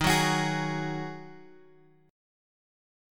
D#mbb5 chord {x 6 4 x 7 4} chord
Dsharp-Minor Double Flat 5th-Dsharp-x,6,4,x,7,4.m4a